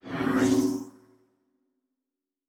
pgs/Assets/Audio/Sci-Fi Sounds/Doors and Portals/Teleport 7_2.wav at master
Teleport 7_2.wav